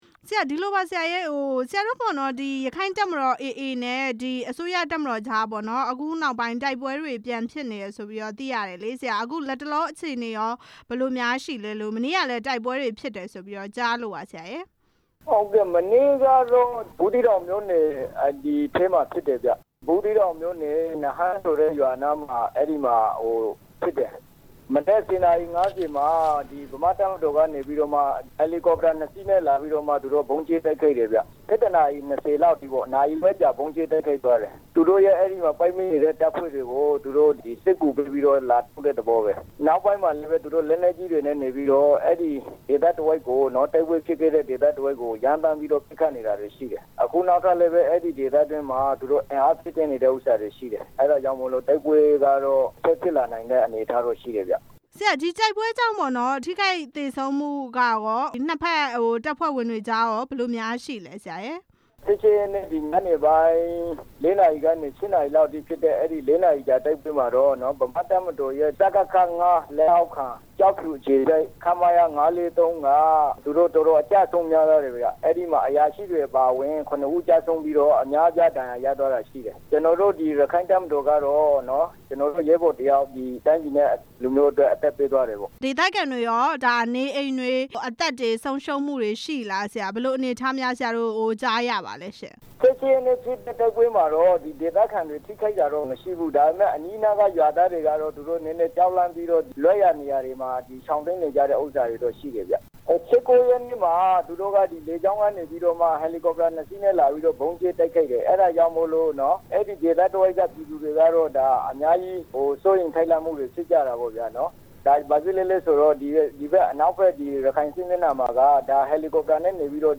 အစိုးရတပ်မတော်နဲ့ ရက္ခိုင့်တပ်မတော် တိုက်ပွဲအကြောင်း မေးမြန်းချက်